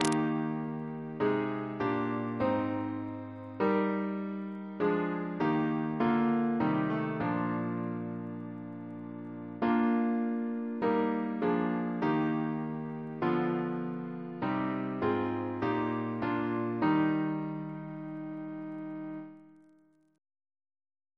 Double chant in E♭ Composer: William H. Gray (1785-1824) Reference psalters: PP/SNCB: 130